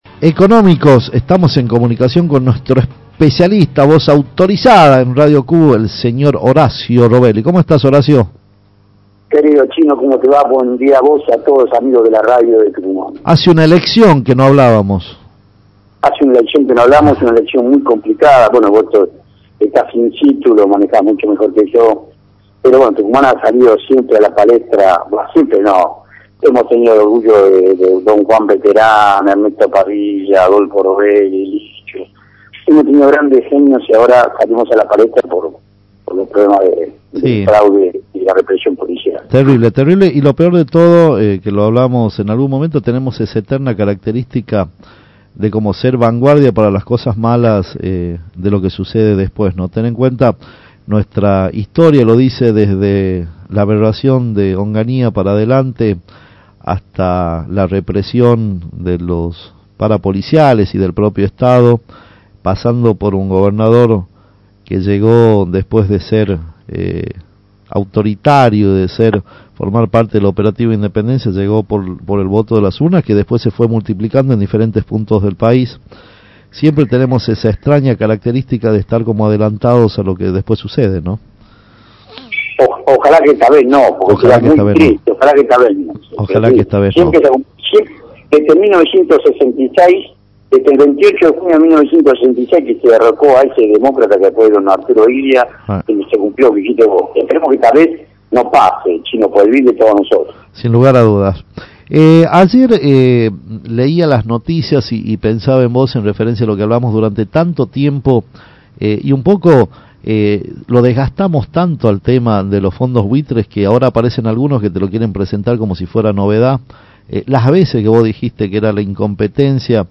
Informe sobre la actividad económica del país